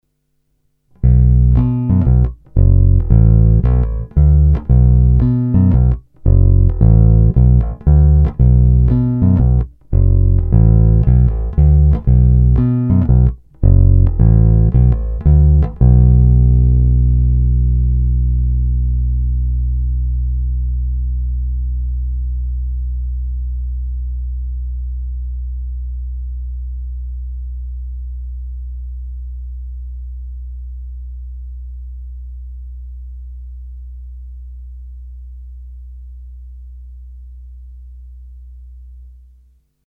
Ani zvukově mě snímač nijak neuráží, produkuje silný signál s dostatkem basů, výšek i tolik potřebných středů, zkrátka poctivý padesátkový preclík, který, když se vezme pořádně za struny, i štěká a kouše.
Není-li uvedeno jinak, následující nahrávky byly provedeny rovnou do zvukové karty a dále kromě normalizace ponechány v původním stavu.
Hra u krku